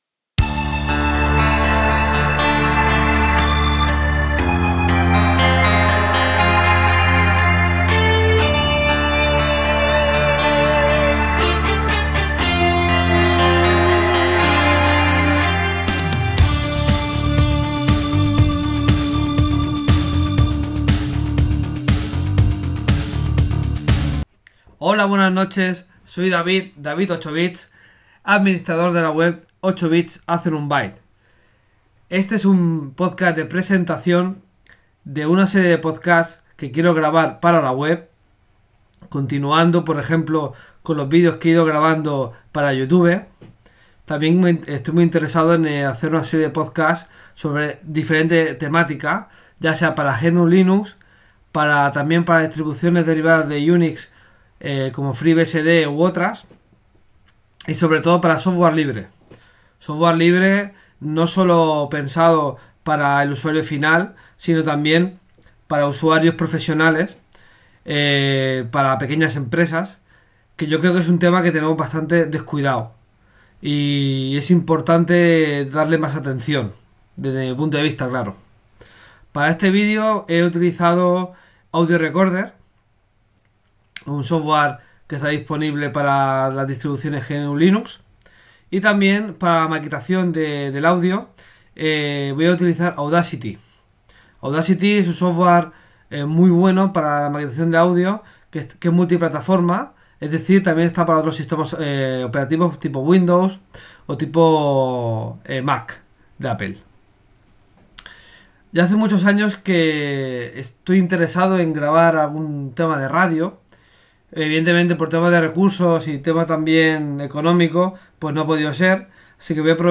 Para grabarlo he utilizado únicamente software libre, concretamente Audio Recorder y Audacity.